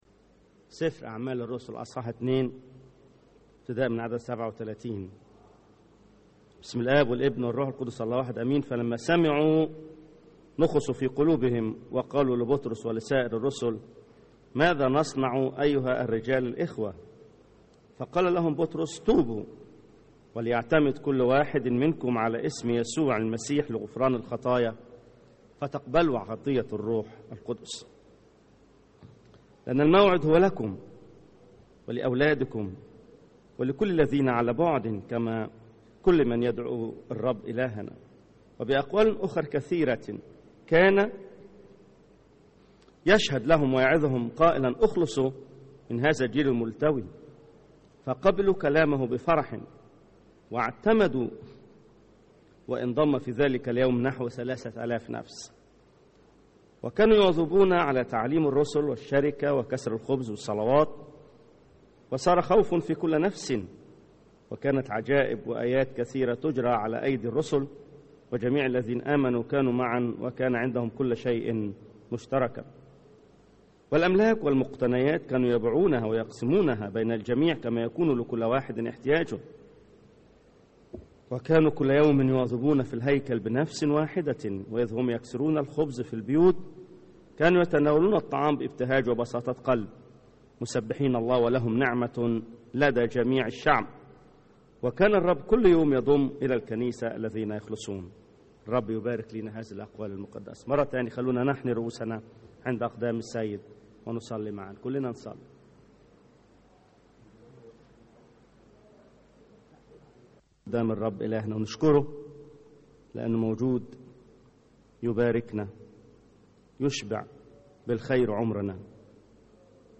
سلسلة من ثلاث عظات عن الروح القدس.. العظة الثانية – نتائج قبول الروح القدس